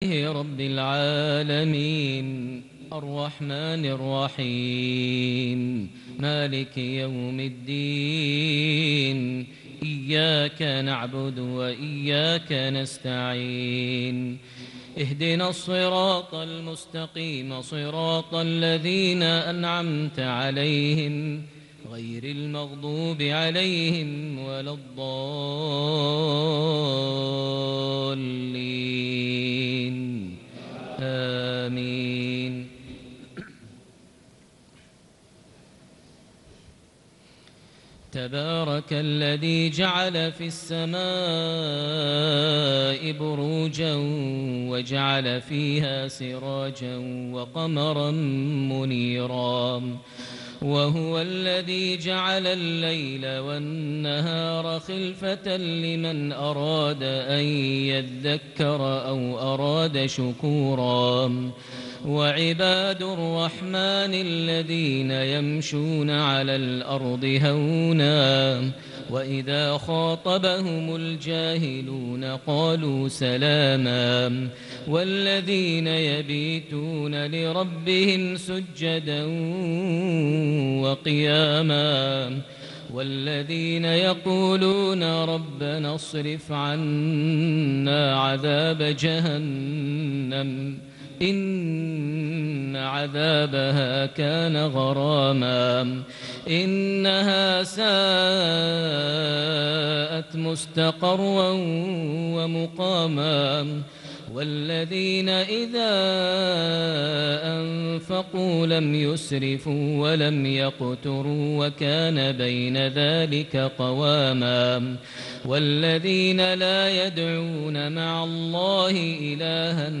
صلاة العشاء ٨ ذي الحجة ١٤٣٨هـ خواتيم سورة الفرقان > 1438 هـ > الفروض - تلاوات ماهر المعيقلي